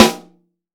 Index of /90_sSampleCDs/AKAI S6000 CD-ROM - Volume 3/Snare1/PICCOLO_SN